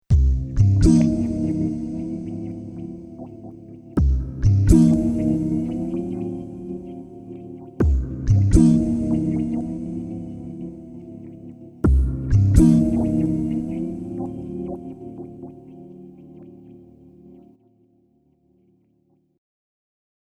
The CD insert has the message "All of the multi-Patch examples and songs were one realtime Performance on an XV-5080. No audio overdubbing was used to exceed the realtime capability of the unit."
13-vocal-demo-5.mp3